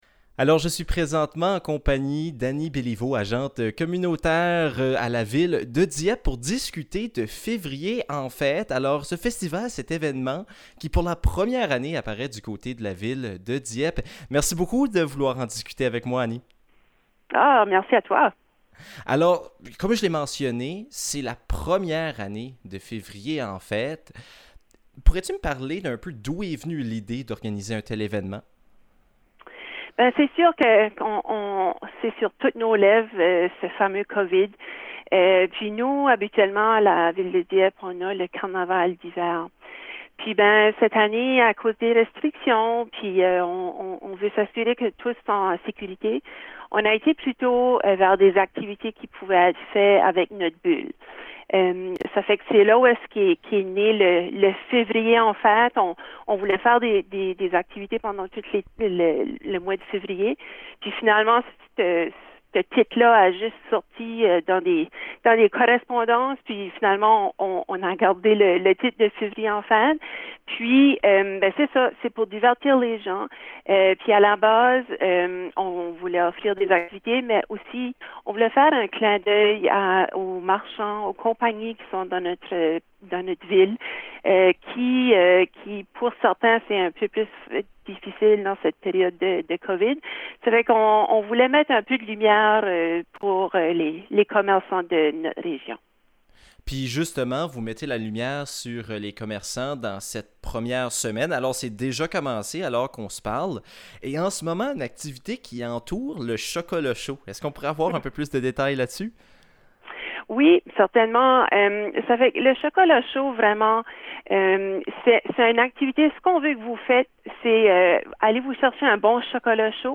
l'entrevue